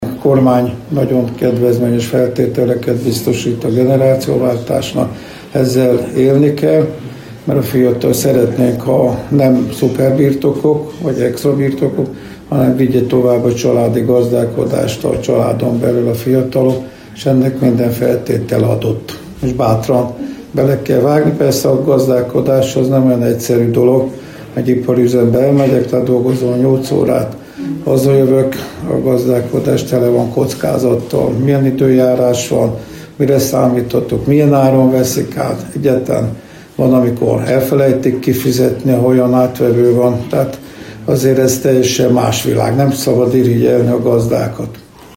A Nemzeti Agrárgazdasági Kamara és a Magyar Gazdakörök és Gazdaszövetkezetek Szövetsége közös szervezésében megrendezett eseményen a környék gazdálkodóinak tartottak szakmai előadást.
Az eseményen részt vett Süli János, a térség országgyűlési képviselője is, aki többek mellett az agráriumban zajló generációváltás aktualitásairól is beszélt.